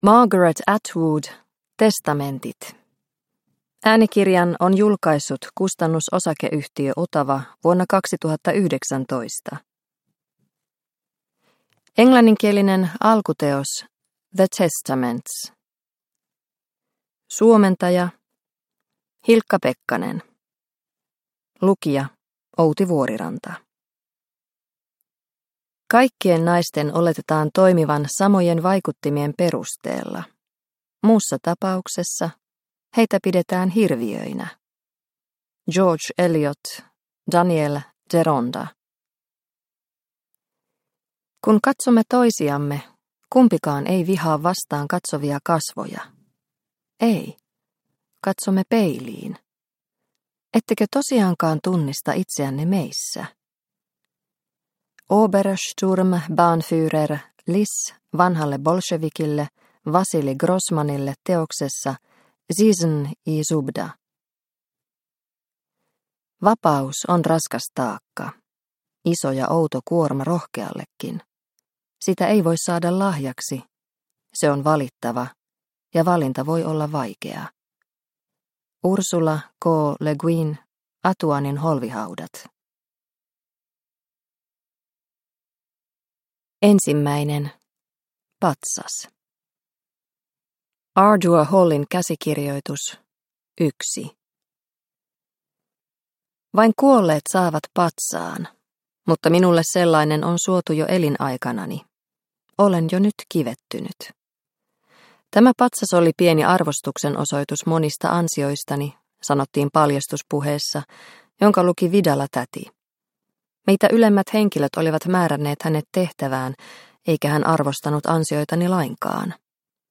Testamentit – Ljudbok – Laddas ner